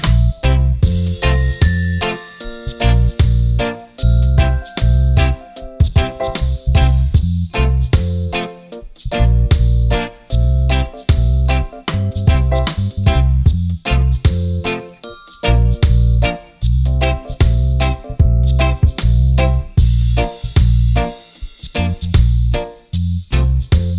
no Backing Vocals Reggae 3:51 Buy £1.50